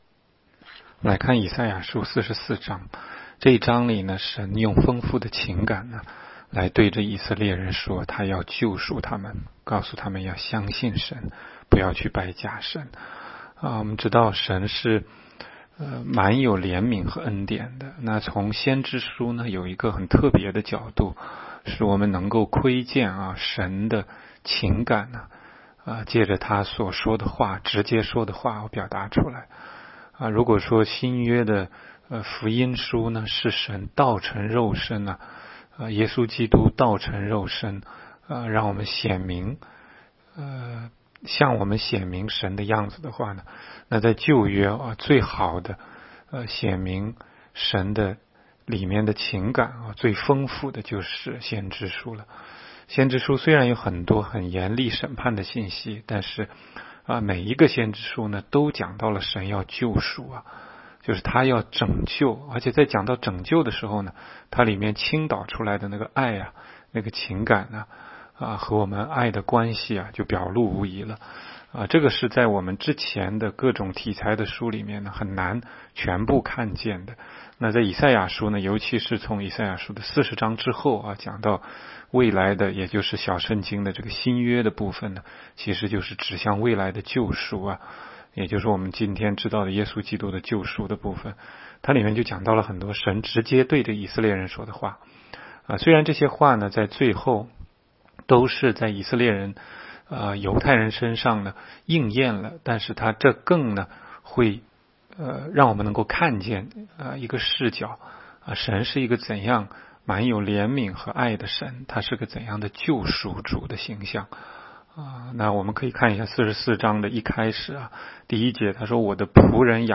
16街讲道录音 - 每日读经 -《以赛亚书》44章